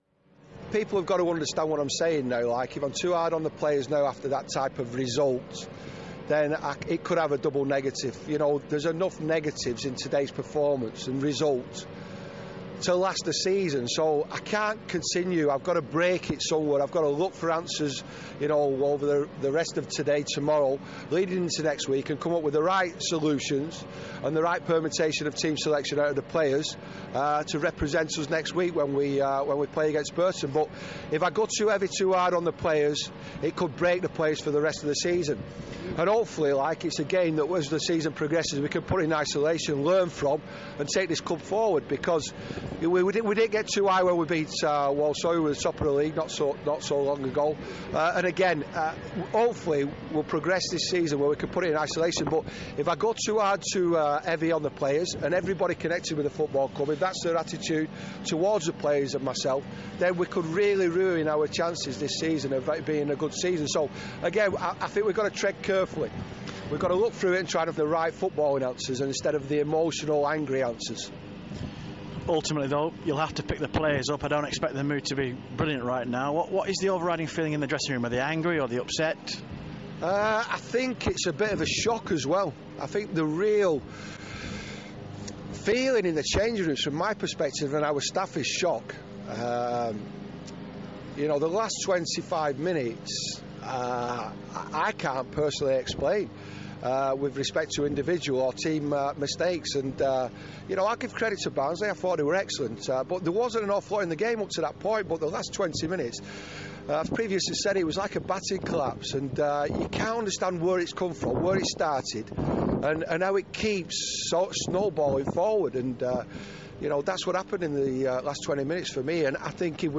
Rochdale manager Keith Hill says he couldn't explain why his team made so many mistakes as Rochdale slumped to a heavy 6-1 defeat away to Barnsley.